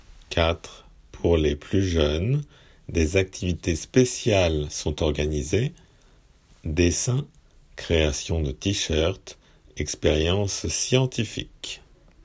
仏検受験用　聞き取り正誤問題－音声